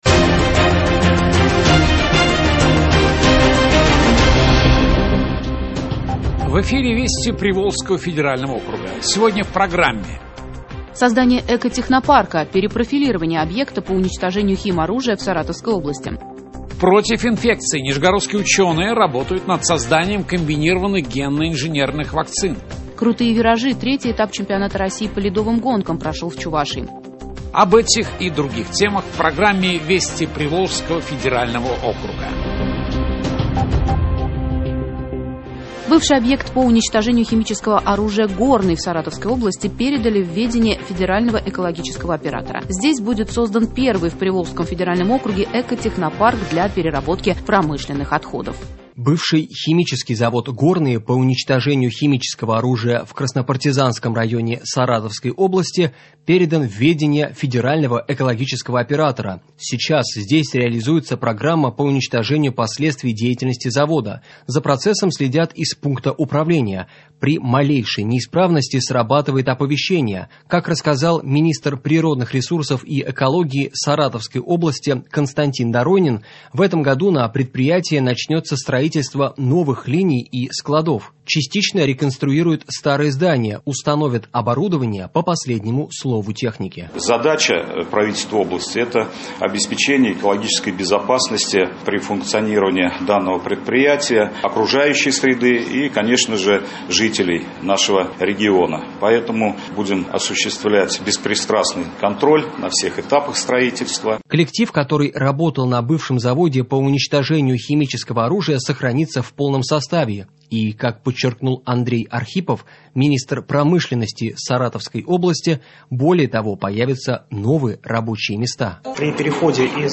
Радиообзор событий регионов ПФО.